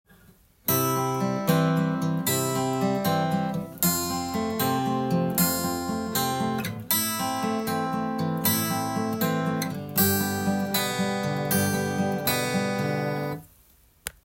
【ピック＆指弾きハイブリット練習２】
②は、①同様　小節最初にベース音と１弦の指弾きが入ってきますが
３拍目にもベース音と指弾きが入ってきます。